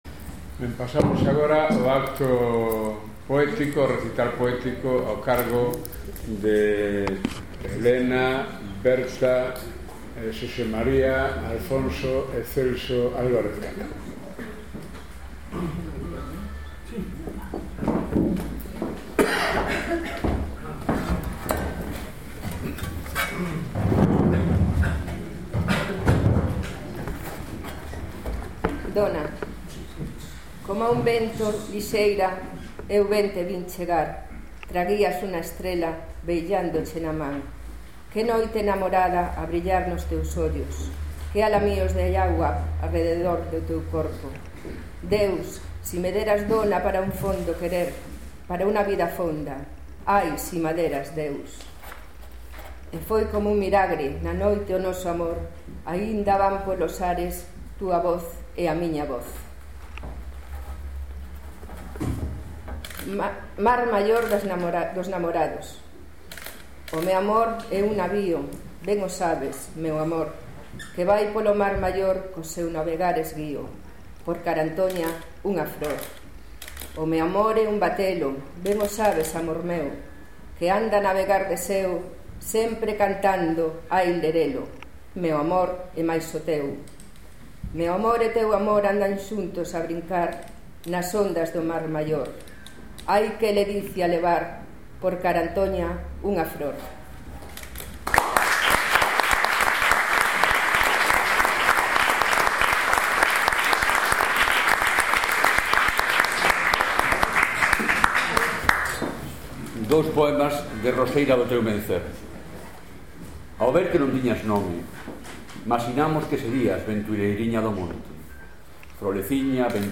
Vigo, Casa Galega da Cultura, 20 h. Acto dentro do ciclo A palabra e os días
Recital poético 9 m. escutar